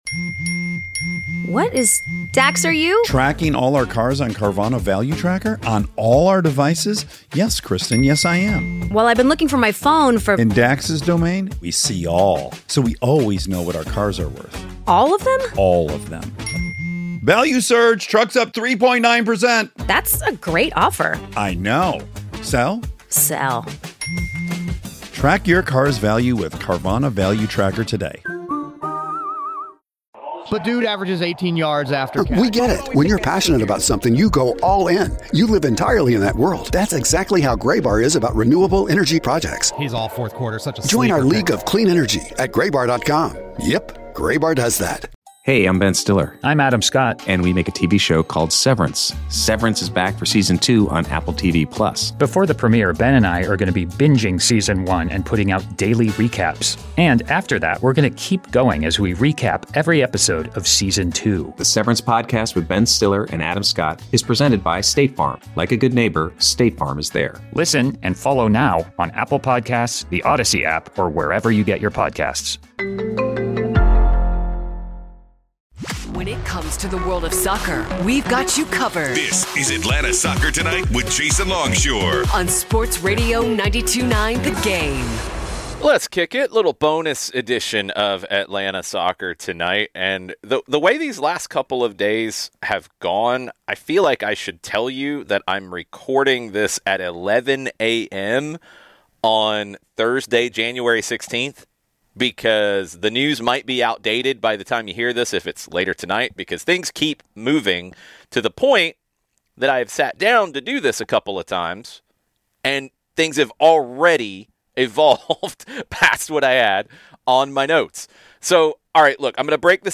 Sports Radio 92-9 The Game coverage of Atlanta United and MLS with weekly podcasts and interviews including Atlanta United players and executives.